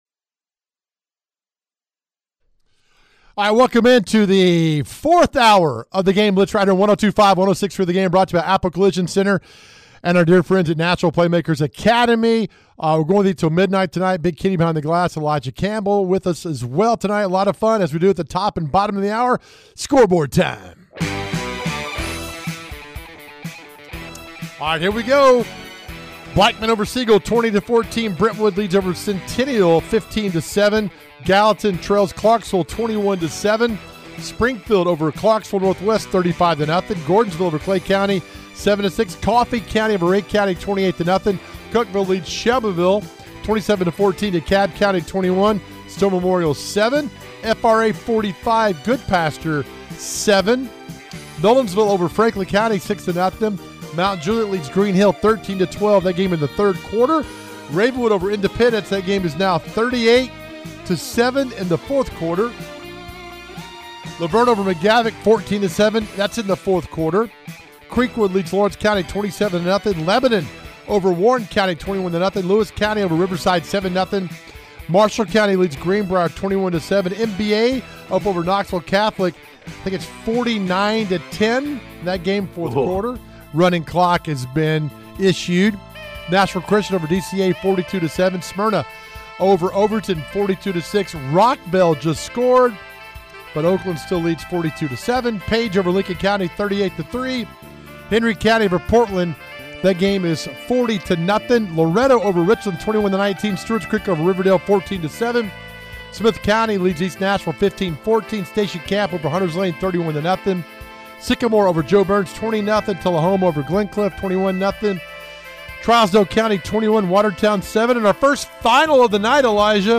We have reporters on location as well as coaches interviews and scoreboard updates. Tune in right here for full High School Football coverage!